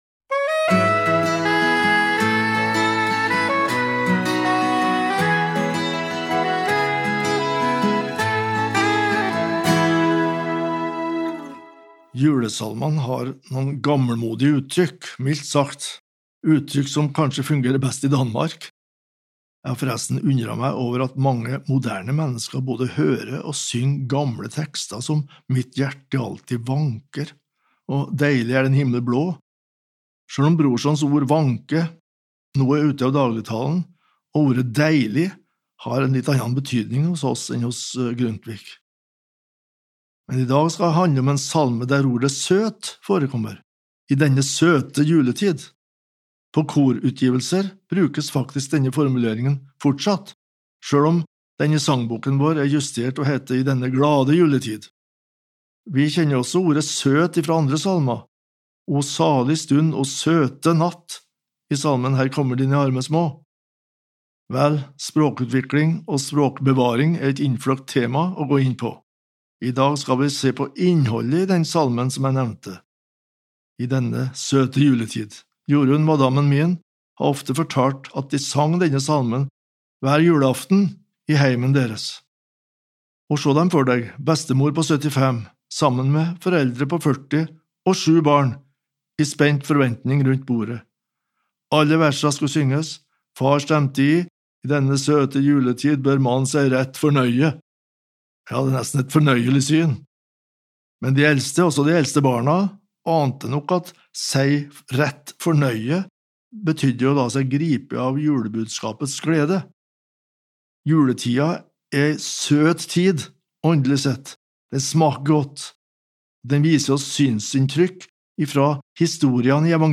Daglige andakter (mand.-fred.).